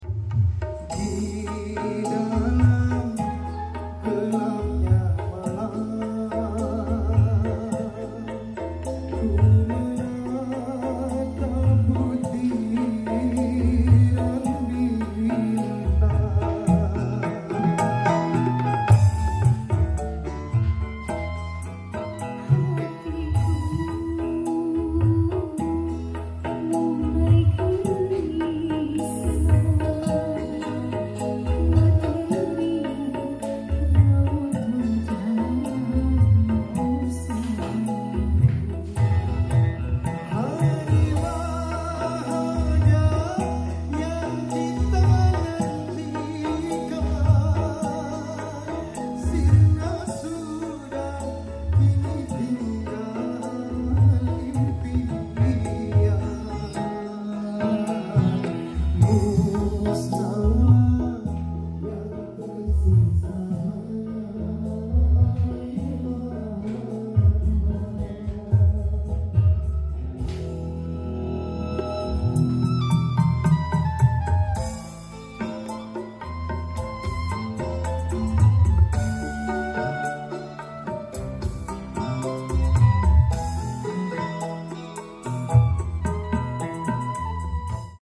CEKSOUND SPEAKER AKTIF SPL AUDIO Sound Effects Free Download